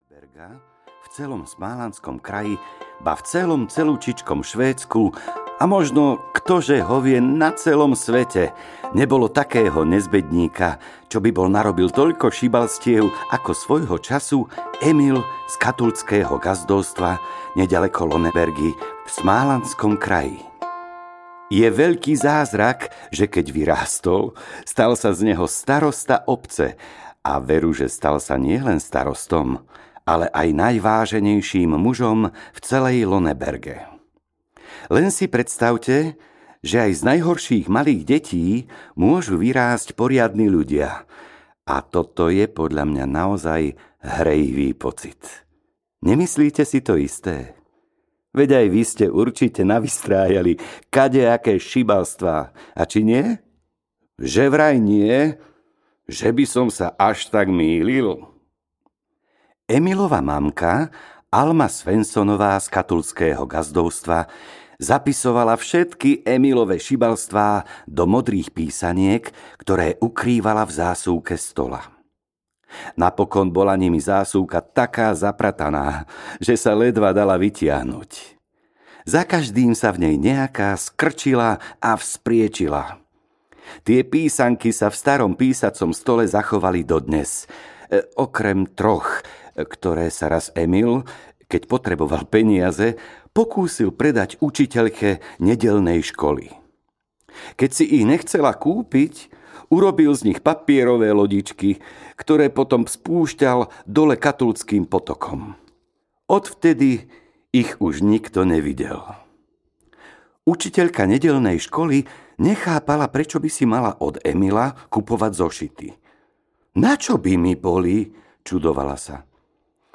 To vymyslí len Emil z Lönnebergy audiokniha
Ukázka z knihy
• InterpretMiro Noga